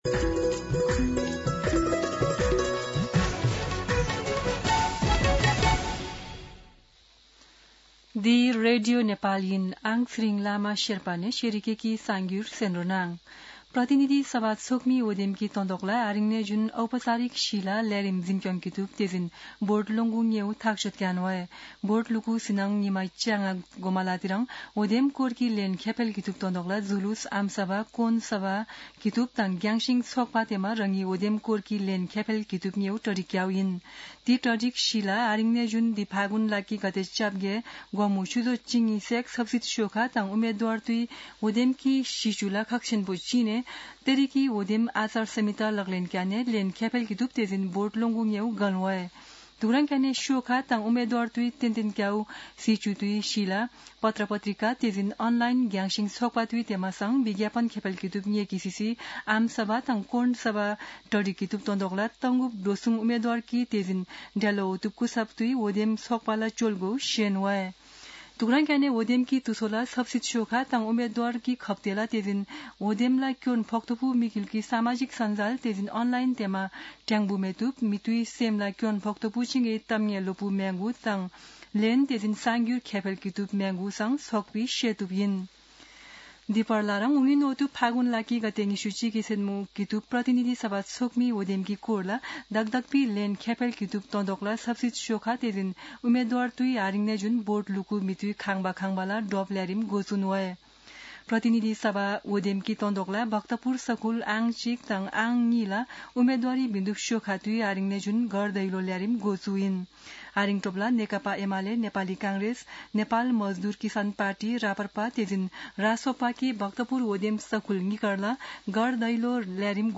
शेर्पा भाषाको समाचार : ४ फागुन , २०८२
Sherpa-News-11-4.mp3